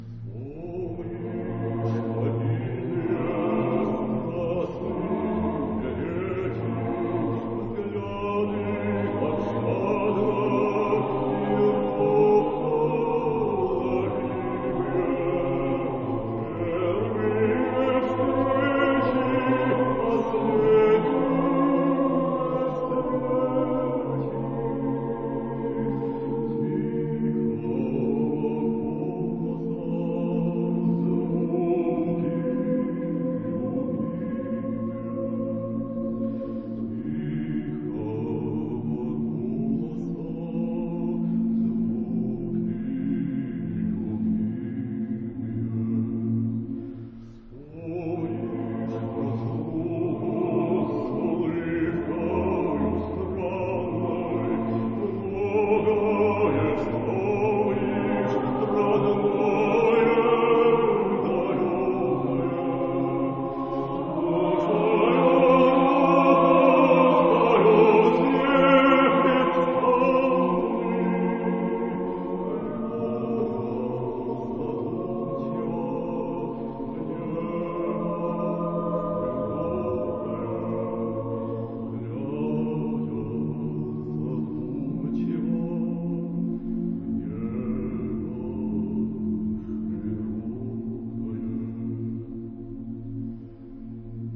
別忘了這張合唱
由俄國葉卡捷琳堡愛樂合唱團，